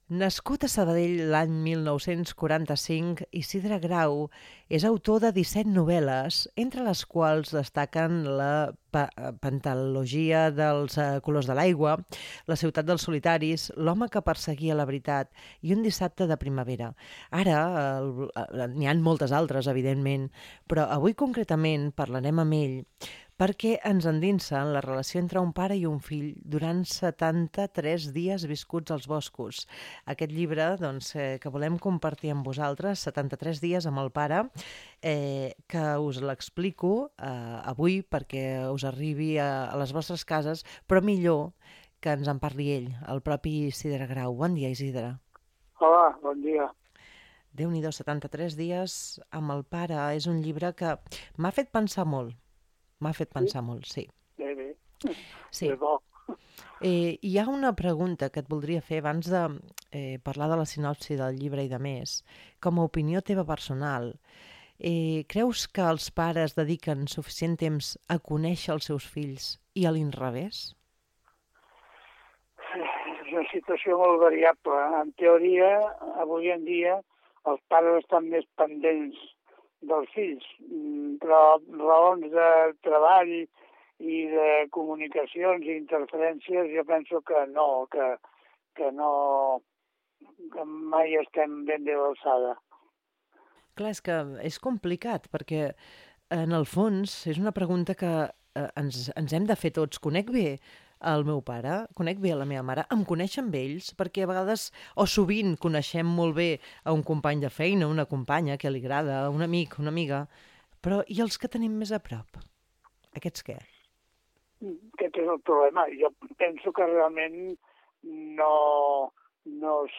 Entrevista a propòsit de Setanta-tres dies amb el pare